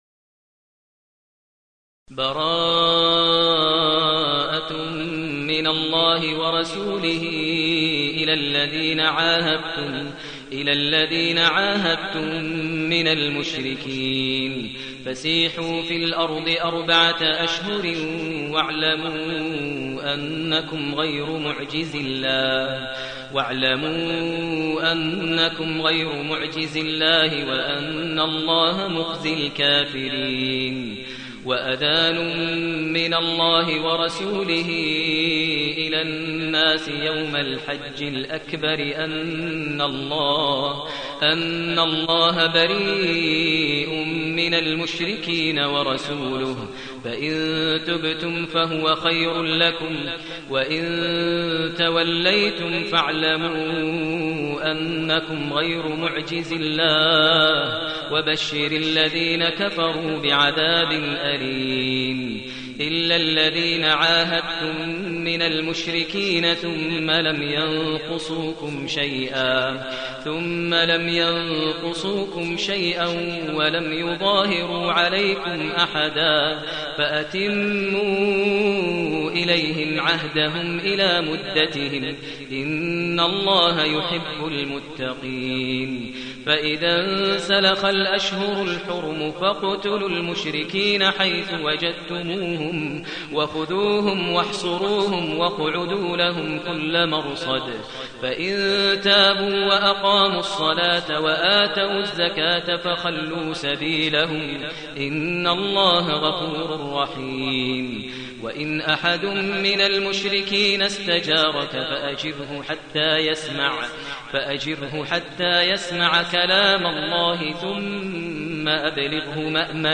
المكان: المسجد الحرام الشيخ: فضيلة الشيخ ماهر المعيقلي فضيلة الشيخ ماهر المعيقلي التوبة